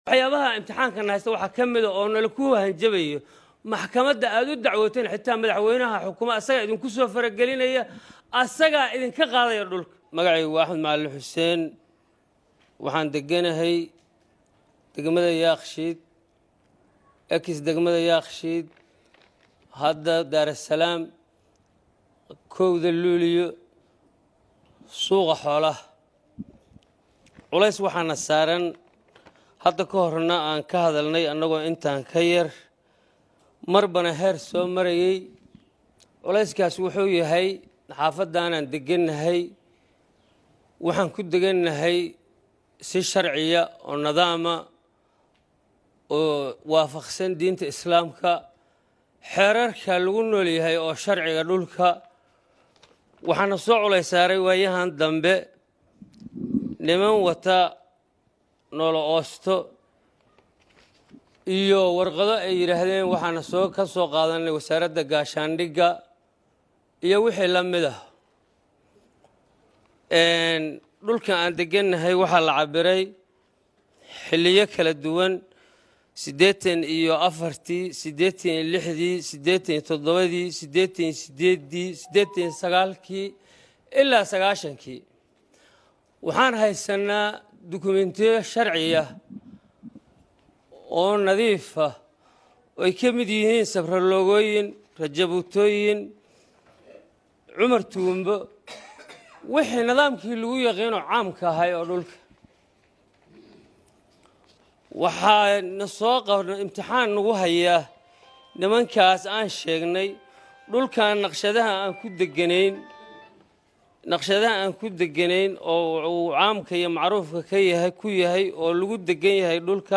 codka-shacabka-muqdisho.mp3